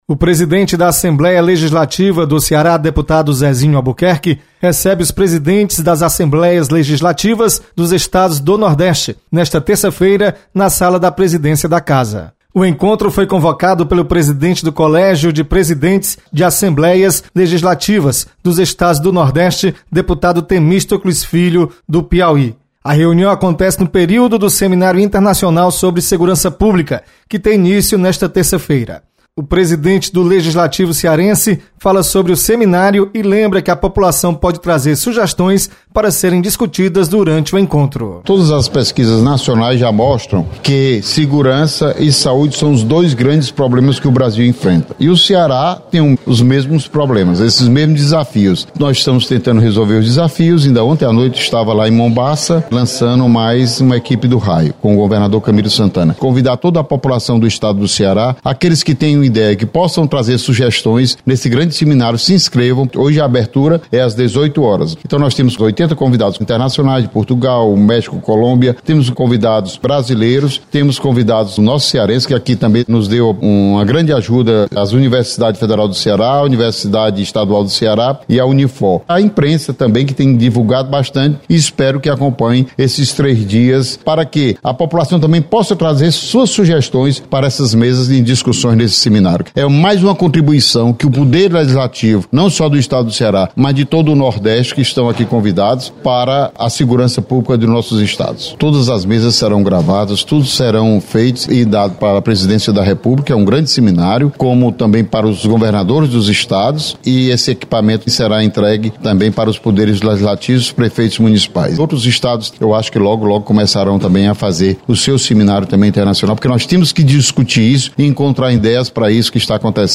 Deputados de vários estados do Nordeste visitam a Assembleia Legislativa. Repórter